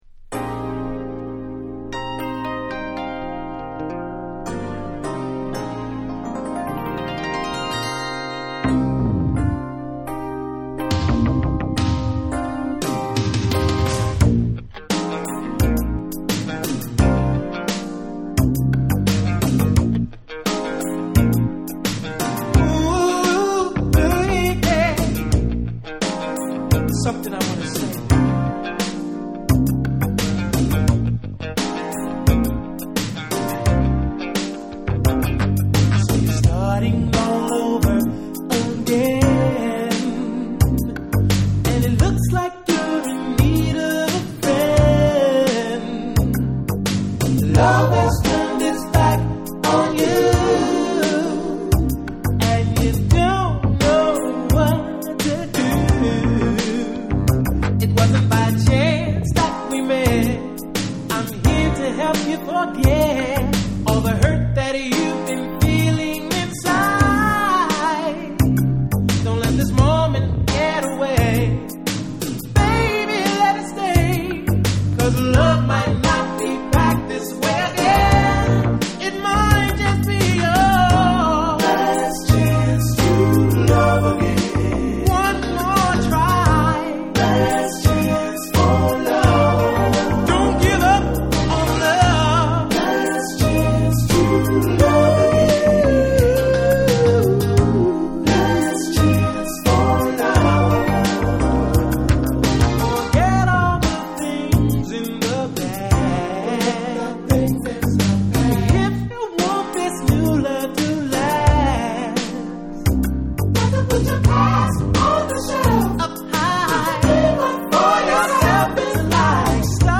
DANCE CLASSICS / DISCO